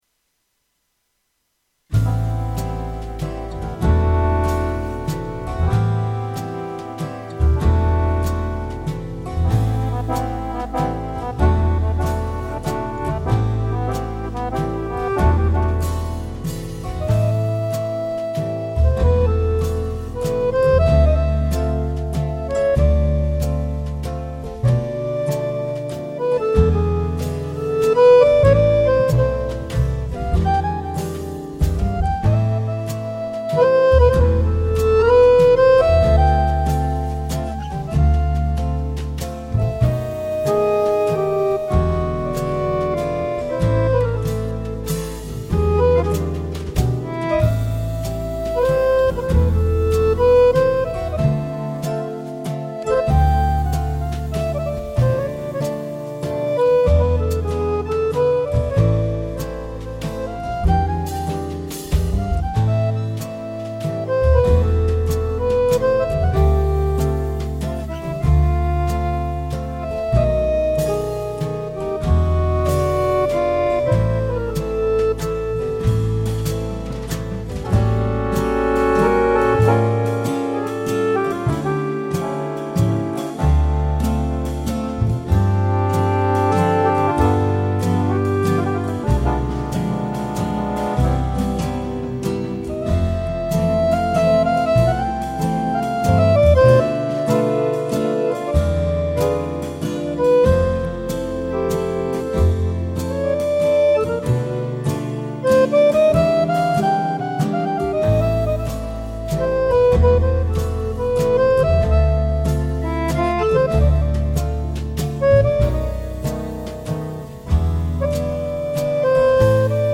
Playalong